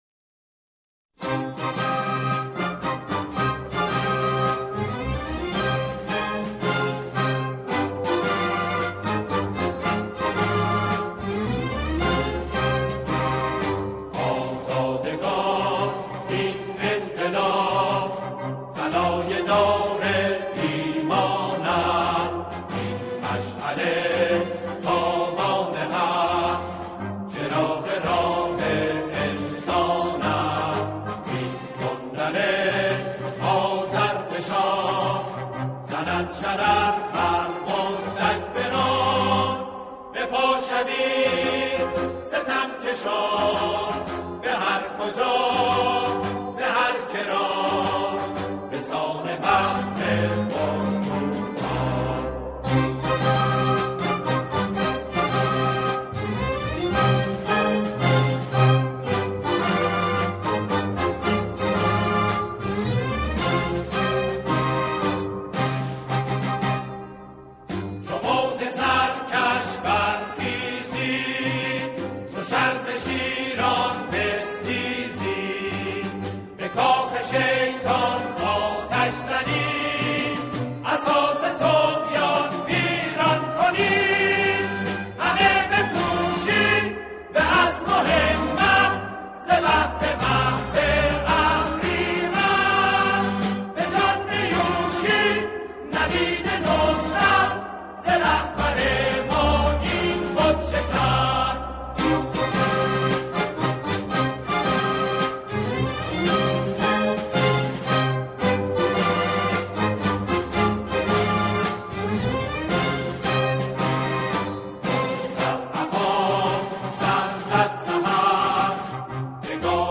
سرود قدیمی